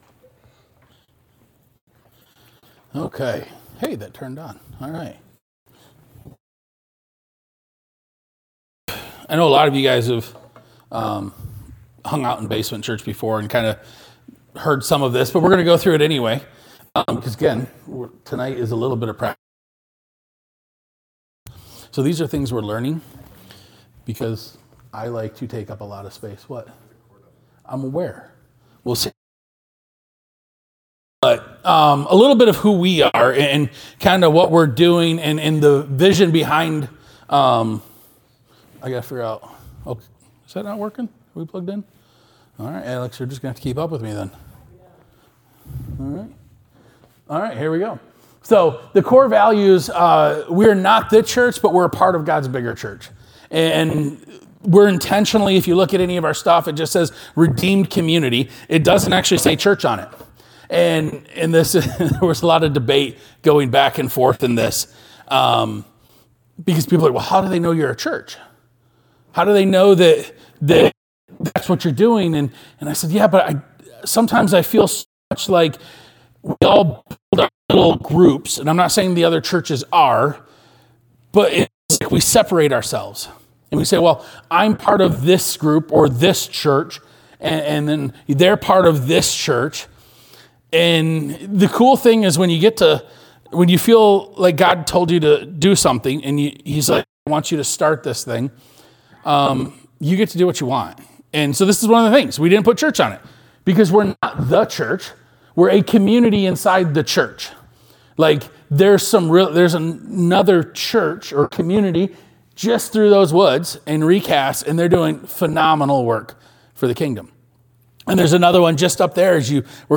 Sermon 11-16.mp3